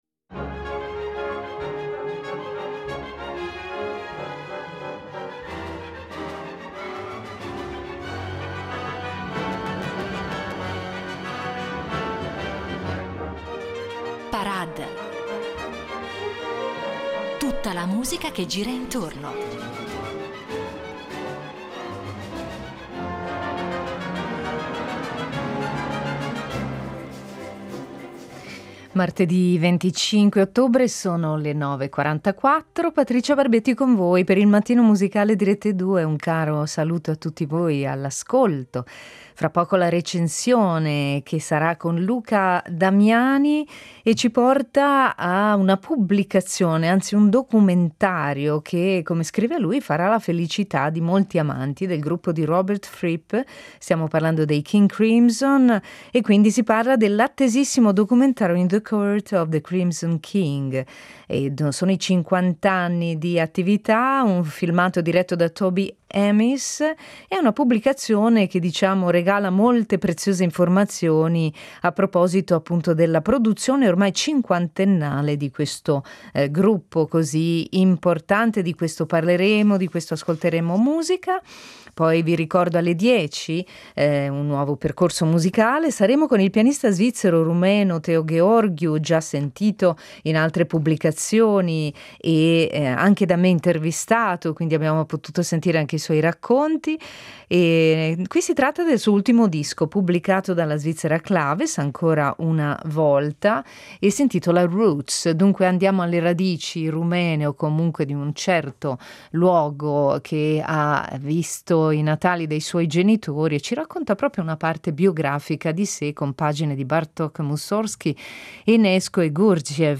La Recensione